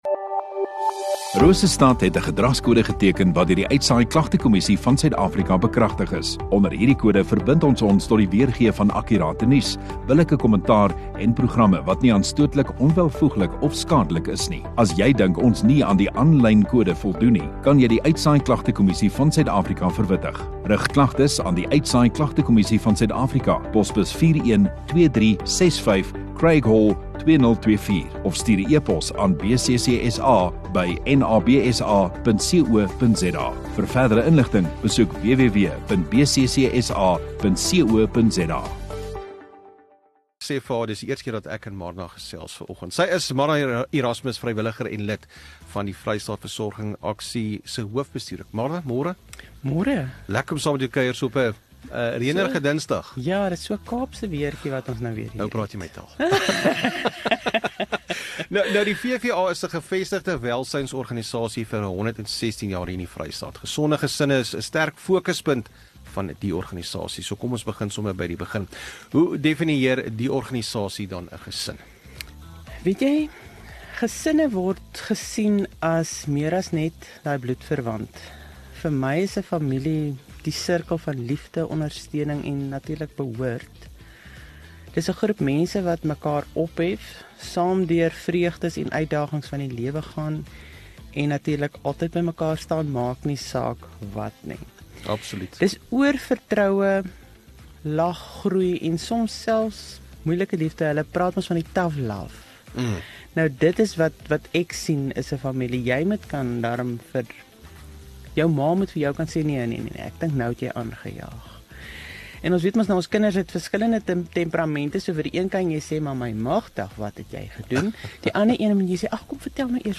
View Promo Continue Radio Rosestad Install Gemeenskap Onderhoude 8 Apr VVA Vrystaat nasorgsentrum